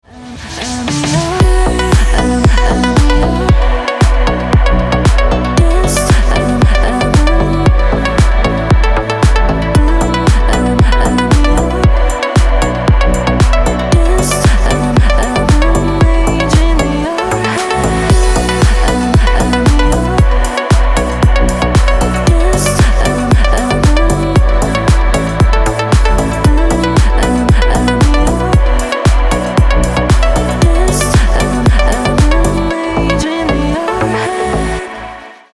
громкие
deep house
красивый женский голос